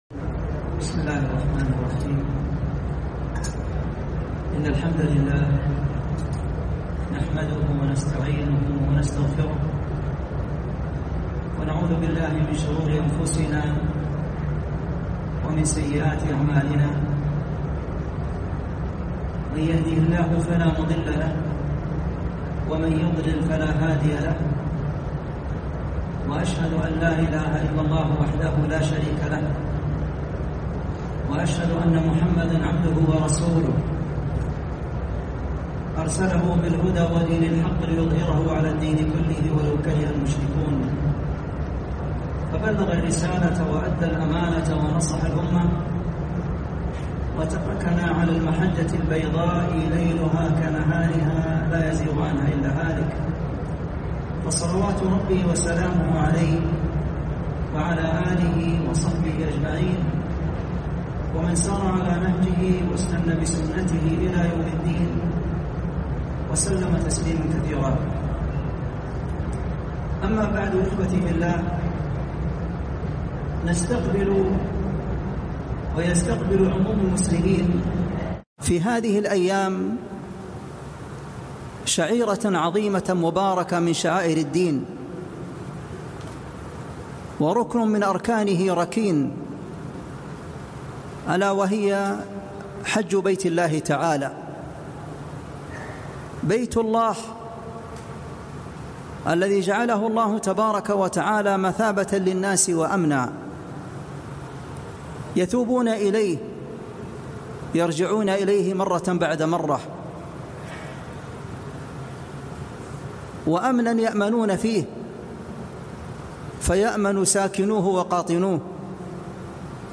كلمة - الحج وذكر الله تعالى